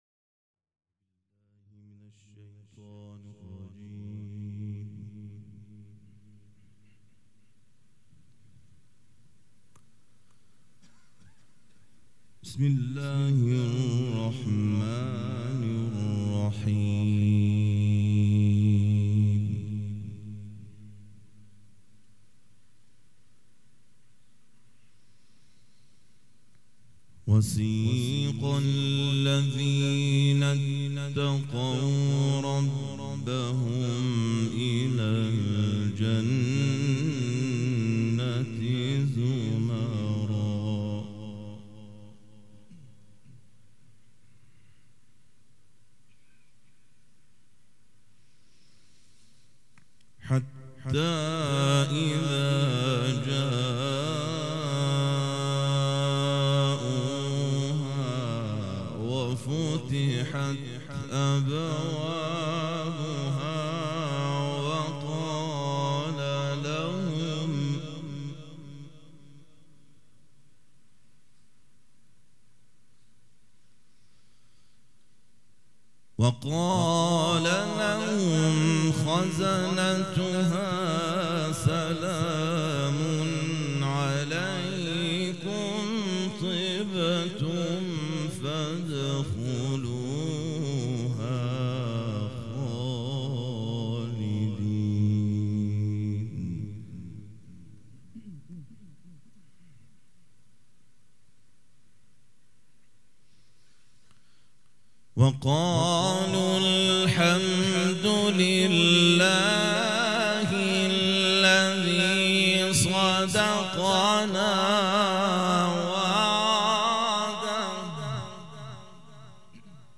قرائت قرآن
قرائت قرآن کریم
مراسم عزاداری شب پنجم